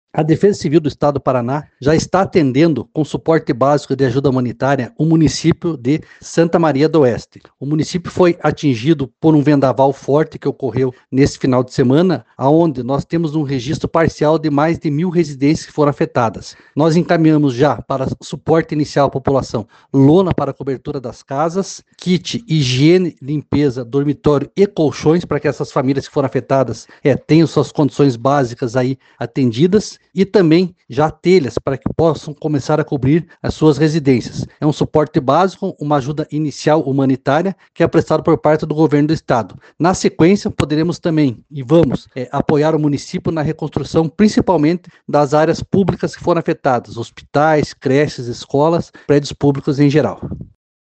Sonora do coordenador da Defesa Civil, Fernando Schunig, sobre a ajuda humanitária enviada para Santa Maria do Oeste e Dois Vizinhos